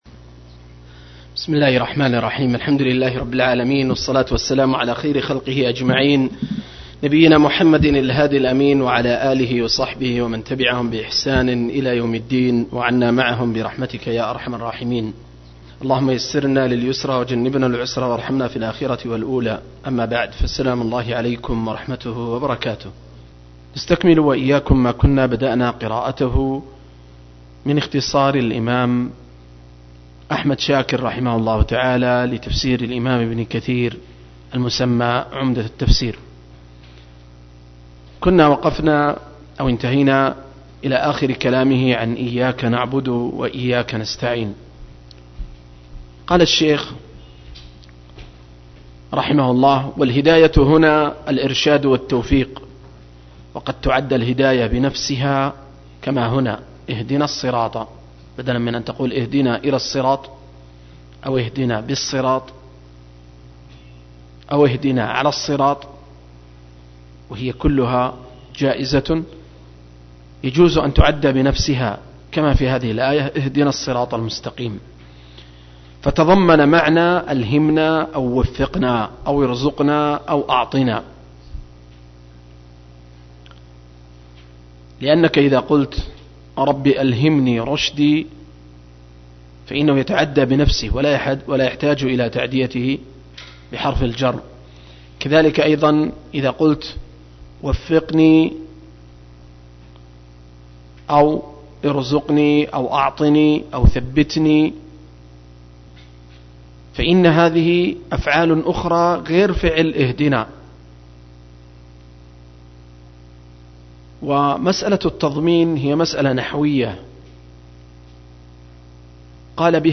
006- عمدة التفسير عن الحافظ ابن كثير رحمه الله للعلامة أحمد شاكر رحمه الله – قراءة وتعليق –